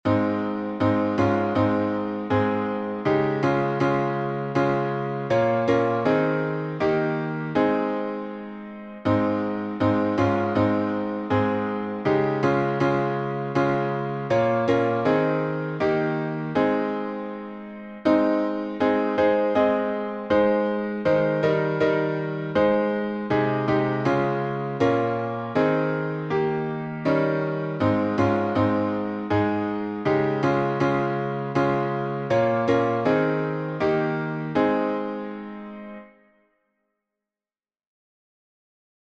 #5023: My Faith Has Found a Resting Place — A flat major in 3/4 | Mobile Hymns
Words by Lidie H. Edmunds, 1891Tune: LANDAS, Norwegian melody, arranged by William J. Kirkpatrick (1838-1921)Key signature: A flat major (4 flats)Time signature: 3/4Meter: 8.6.8.6. with RefrainPublic Domain1.